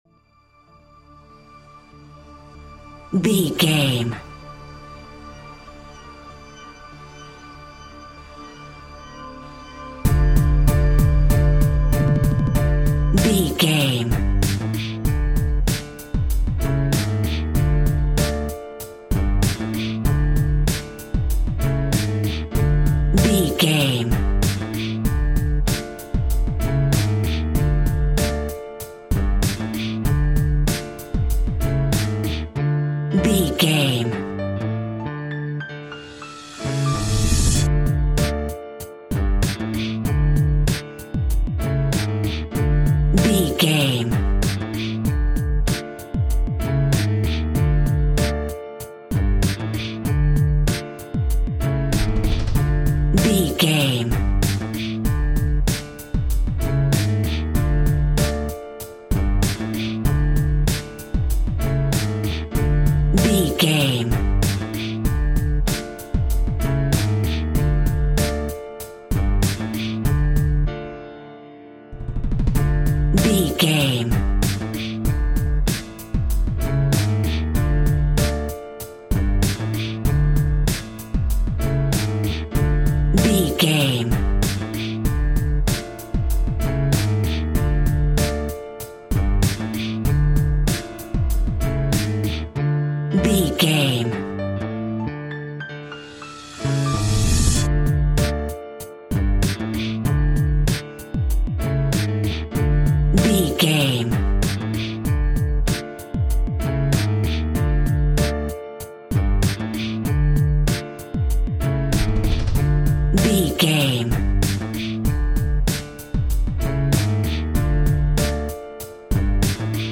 Aeolian/Minor
D
drum machine